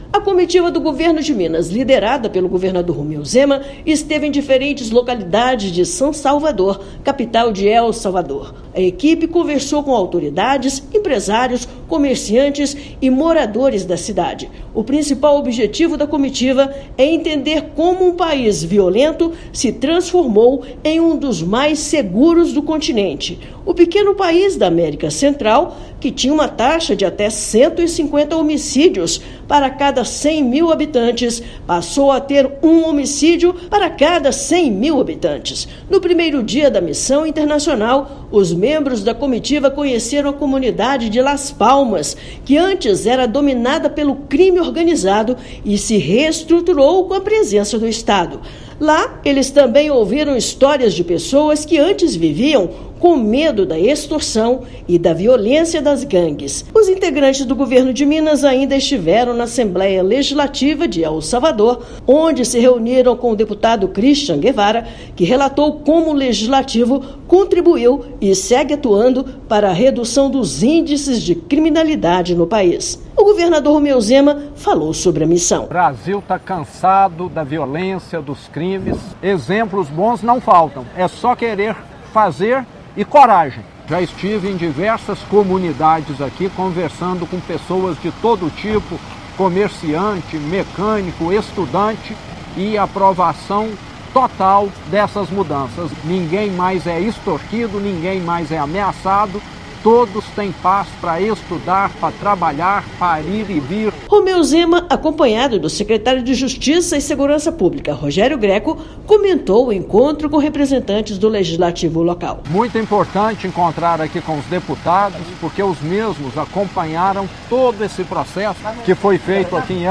[RÁDIO] Em primeiro dia da missão em El Salvador, governador de Minas conhece Assembleia Legislativa nacional e comunidades
Comitiva do Estado está no país da América Central para conhecer iniciativas que levaram a redução drástica nos índices de violência. Ouça matéria de rádio.